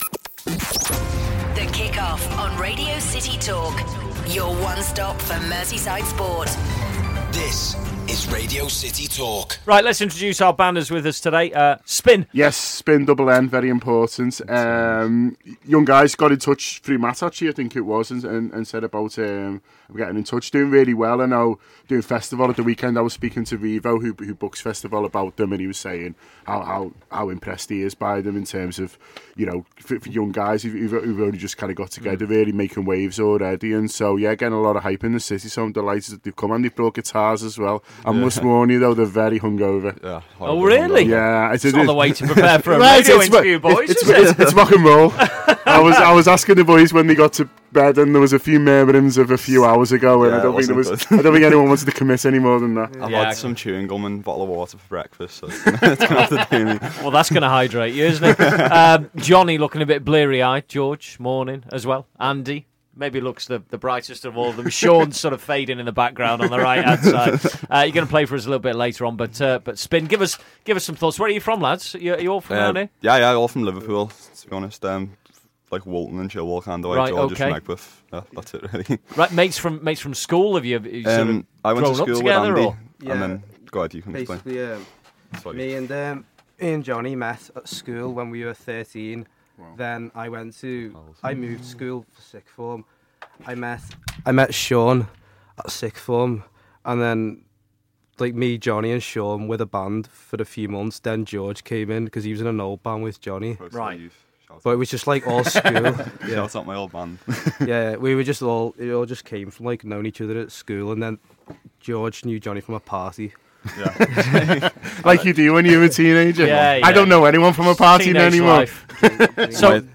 Local band Spinn join us in the studio